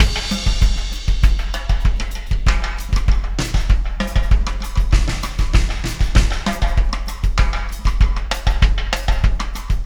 Pulsar Beat 26.wav